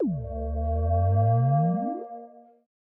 Minecraft Version Minecraft Version 1.21.5 Latest Release | Latest Snapshot 1.21.5 / assets / minecraft / sounds / block / beacon / activate.ogg Compare With Compare With Latest Release | Latest Snapshot